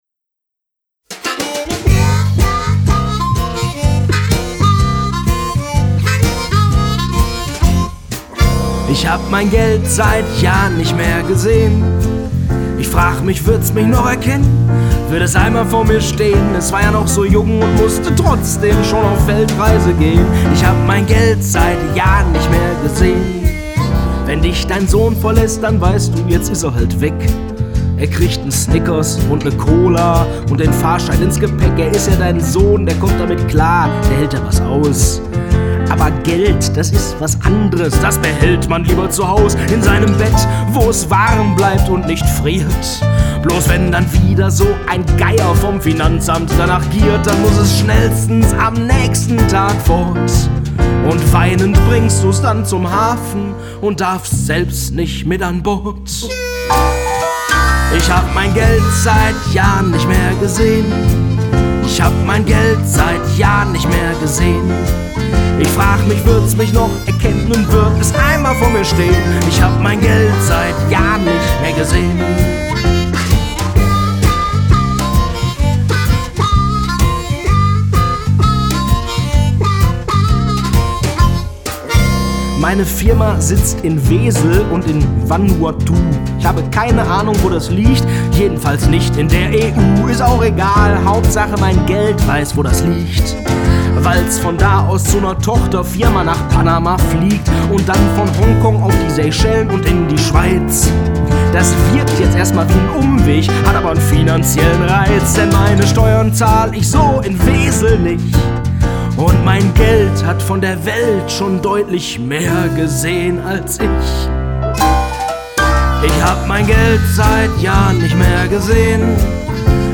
Fünf Stücke sind drauf und sieben Musiker plus ich.